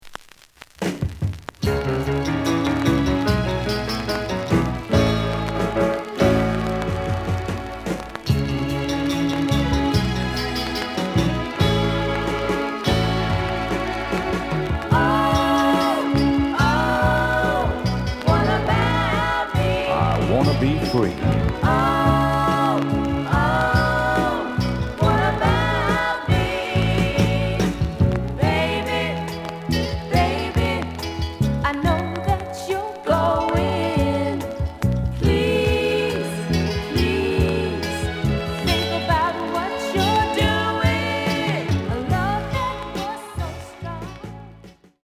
The audio sample is recorded from the actual item.
●Genre: Soul, 70's Soul
Some click noise on B side label due to scratches.